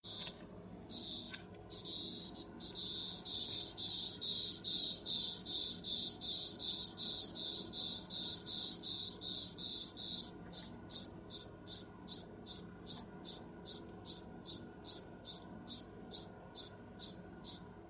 Their songs are essentially identical:
M. tredecula Call:
Note the “tick, tick, tick” rhythm of the call.